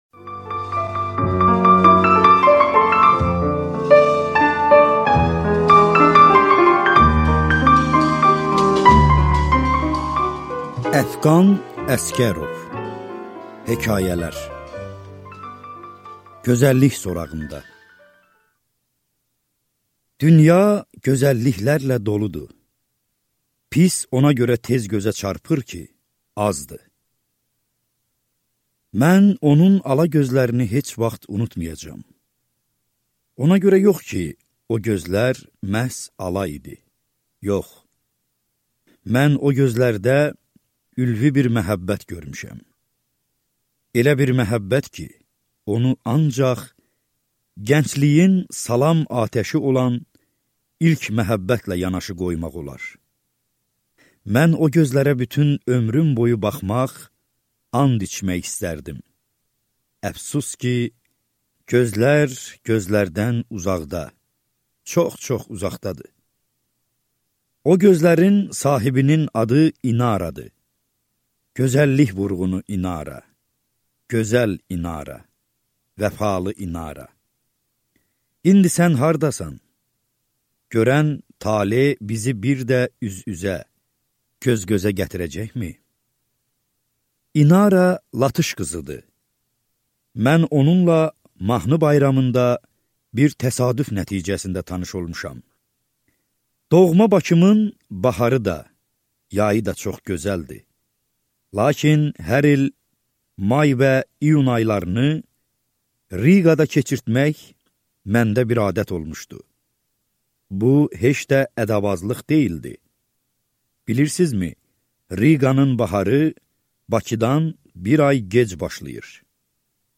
Аудиокнига Əfqan Əsgərovun hekayələri | Библиотека аудиокниг